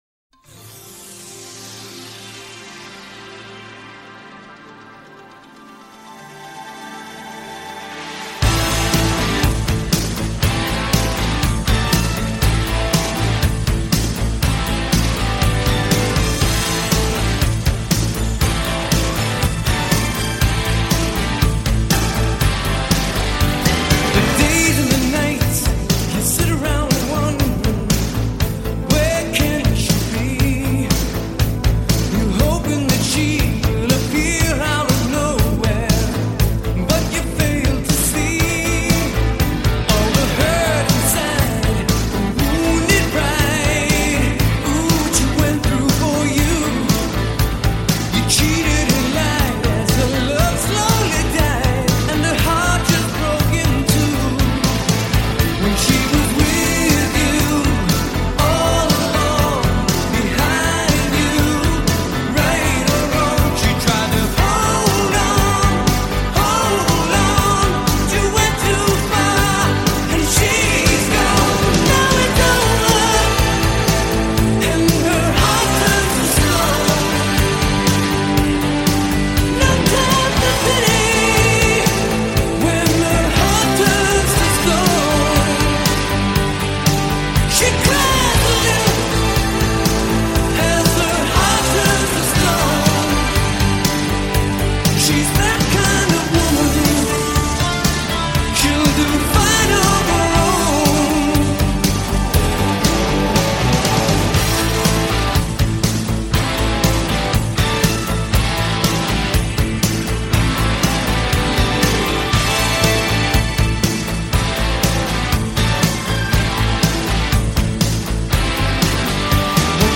Жанр: Classic Rock